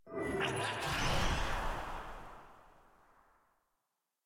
sfx-pm-level-unlock-3.ogg